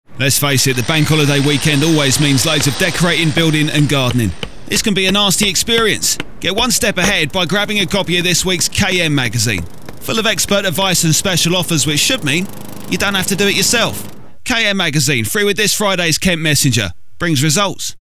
From cool & credible to energetic sports commentator. A hip, young sell for your product.